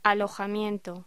Locución: Alojamiento